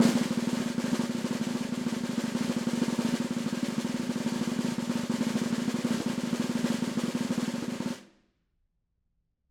Snare2-rollSN_v5_rr1_Sum.wav